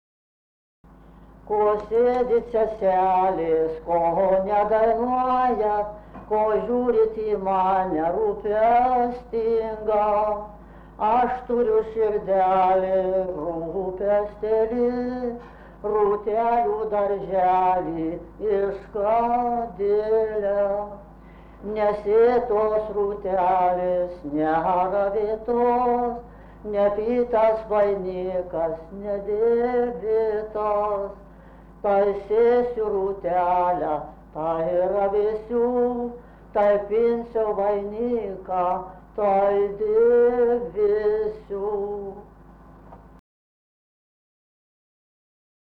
Menčikiai
vokalinis